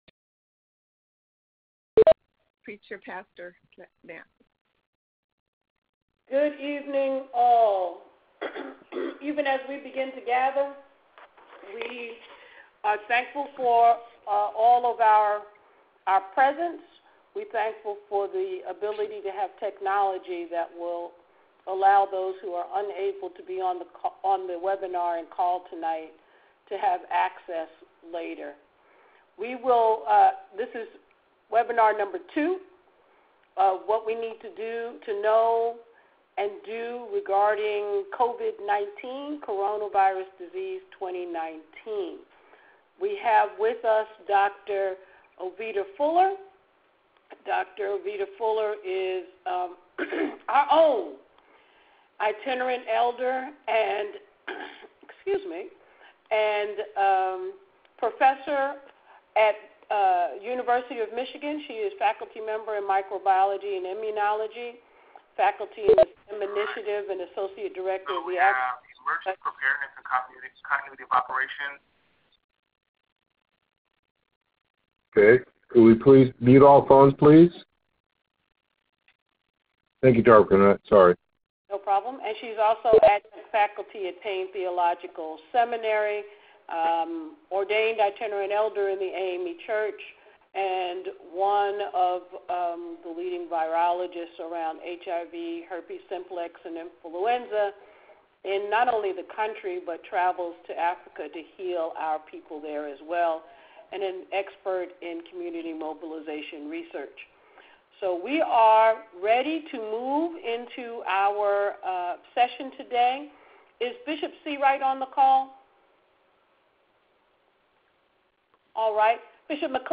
Webinar #2: What We Need to Know and Do: Coronavirus Disease 2019 (COVID-19)